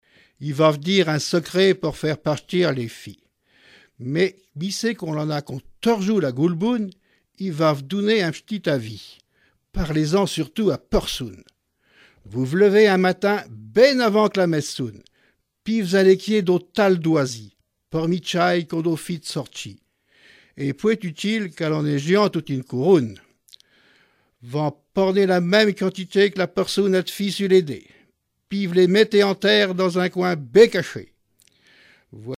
Langue Patois local
Genre poésie
Catégorie Récit